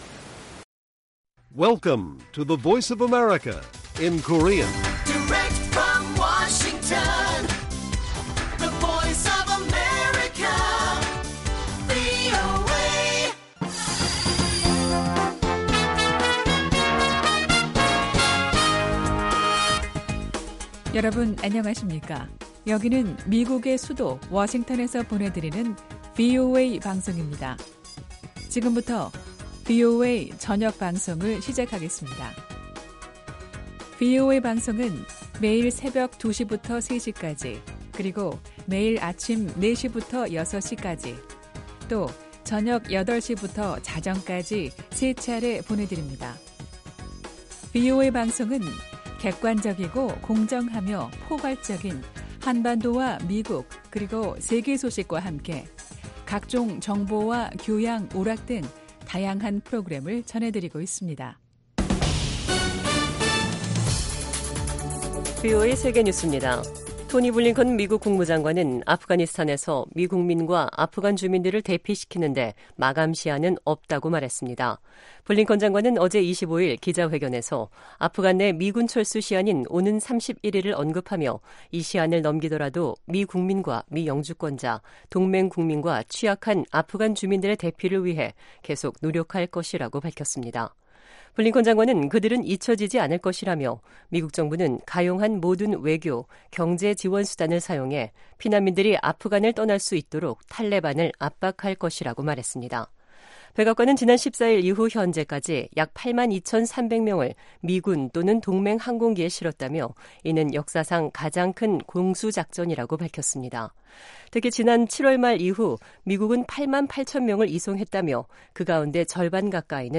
VOA 한국어 간판 뉴스 프로그램 '뉴스 투데이', 2021년 8월 26일 1부 방송입니다. 조 바이든 미국 대통령이 '국가 사이버안보 회의'를 주재하고, 안보 개선을 위한 정부와 민간 부문의 공동 협력 방안을 모색했습니다. 후반기 미-한 연합지휘소 훈련이 26일 종료됐습니다. 미 언론들이 다음 달 1일 만료되는 미국인 북한 여행금지 조치를 집중 조명하고, 대북지원 단체, 한인 이산가족, 전문가 등의 다양한 의견들을 전했습니다.